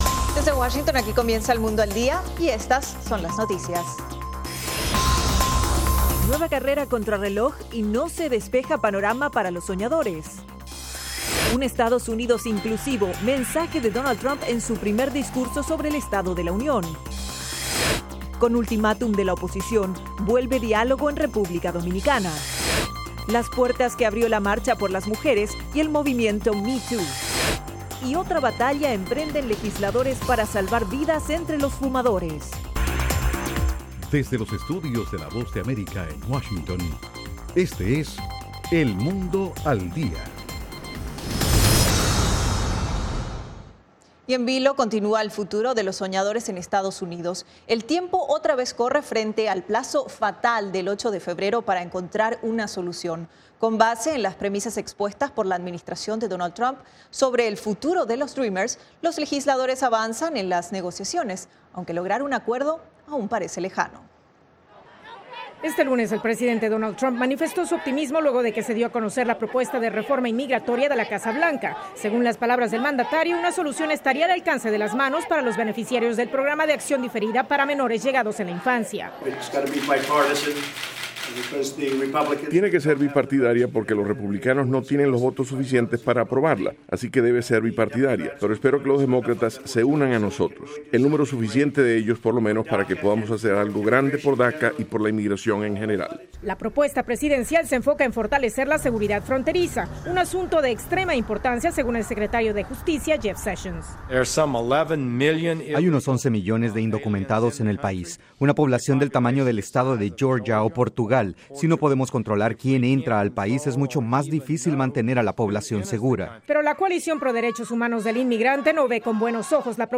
Las noticias del acontecer de Estados Unidos y el mundo con la Voz de América.